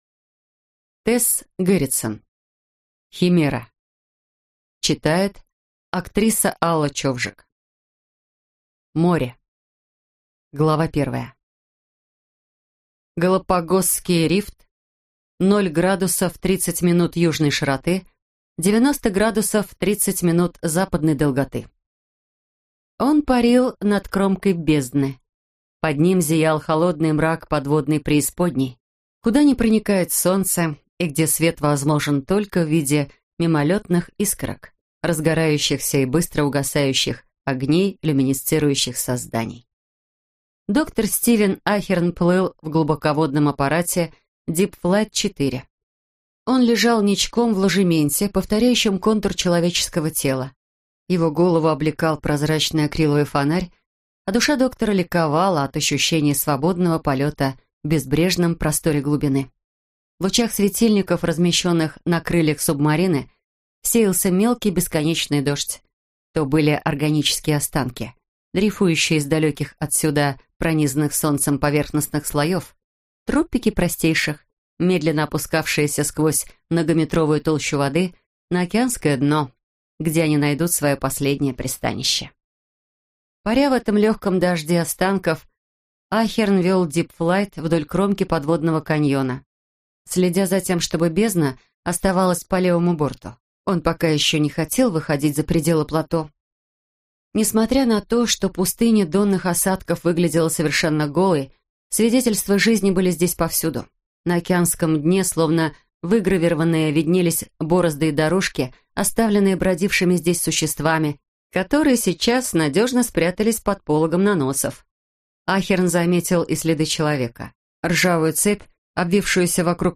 Аудиокнига Химера - купить, скачать и слушать онлайн | КнигоПоиск